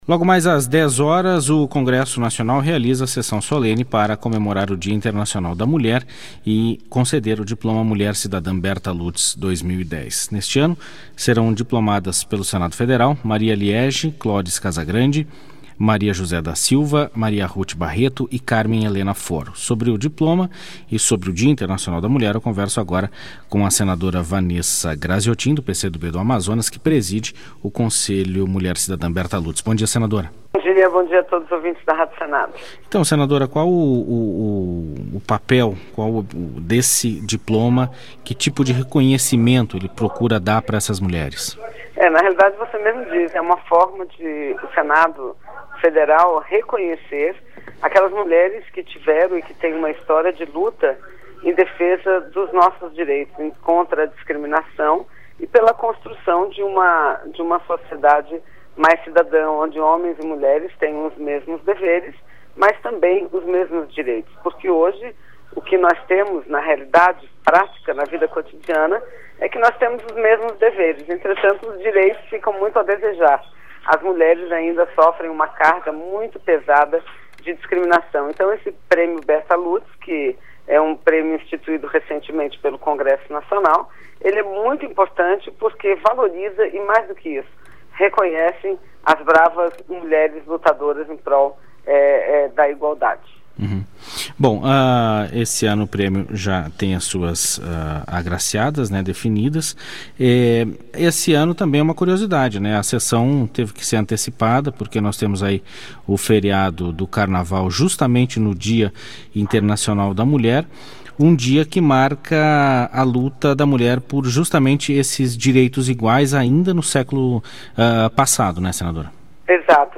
Entrevista com a senadora Vanessa Grazziotin (PCdoB-AM).